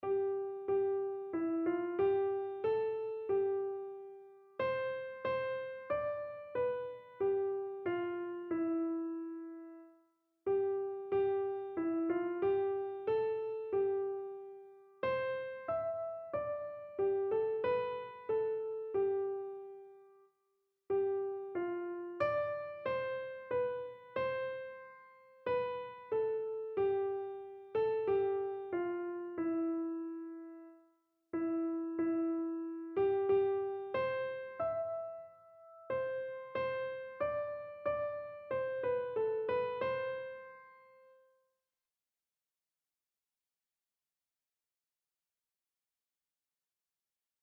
Einzelstimmen (Unisono)
• Sopran [MP3] 741 KB